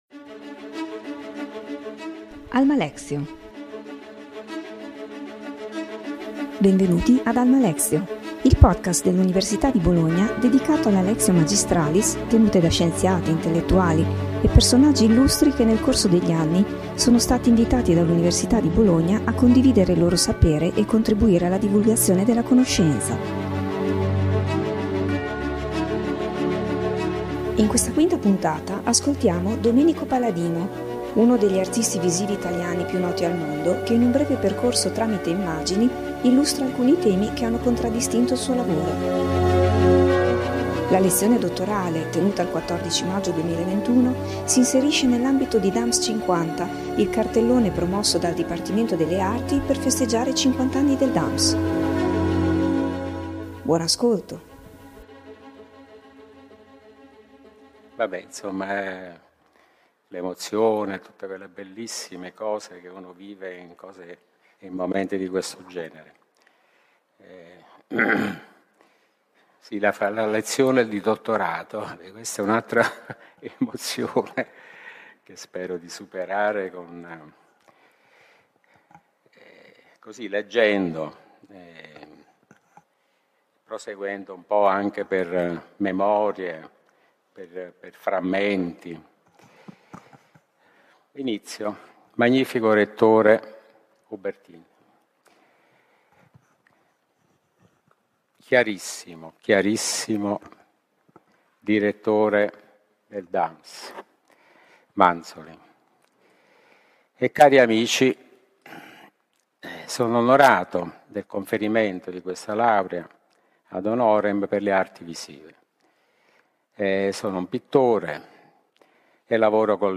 Lo scorso 14 maggio ha ricevuto la laurea ad honorem dell'Alma Mater in Arti visive e la sua lezione magistrale ha affrontato il tema del teatro, dell’immaginazione e dell’arte in generale.